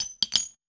weapon_ammo_drop_17.wav